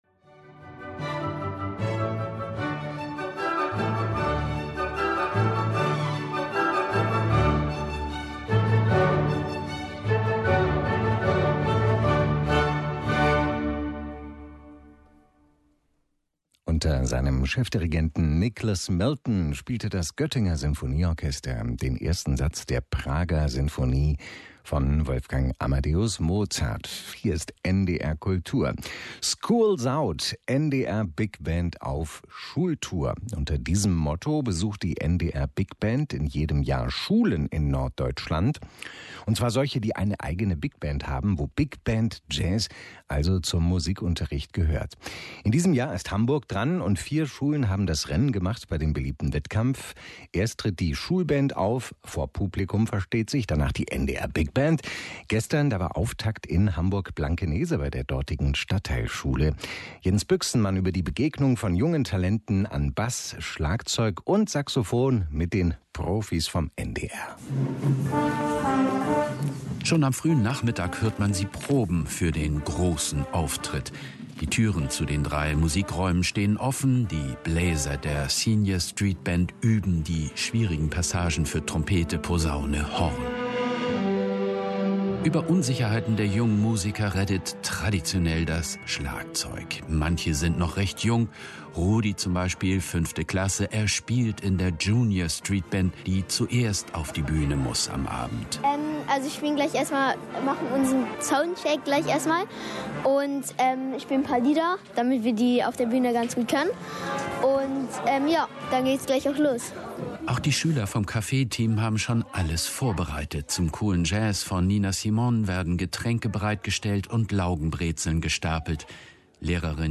NDR-Kultur hat über unser Konzert in einem Radiobeitrag berichtet!